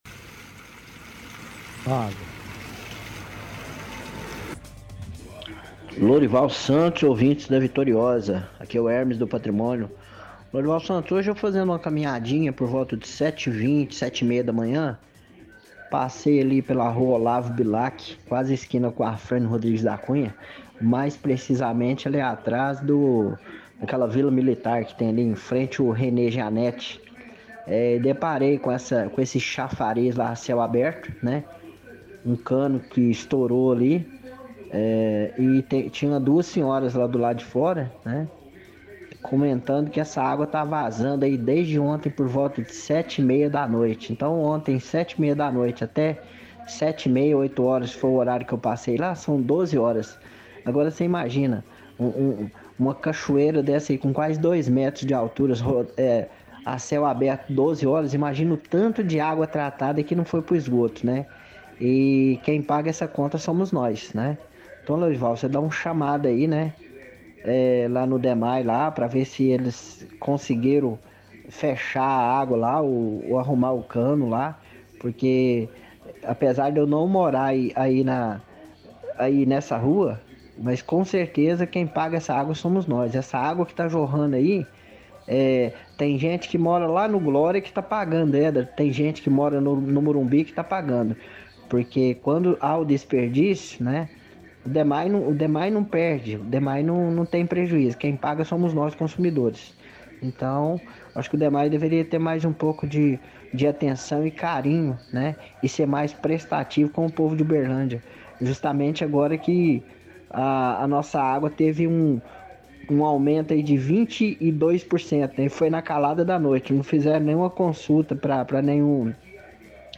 – Ouvinte relata cano estourado em avenida próxima a escola estadual Rene Janete, fala que conversando com pessoas, disseram que estava a mais de 12 horas vazando água.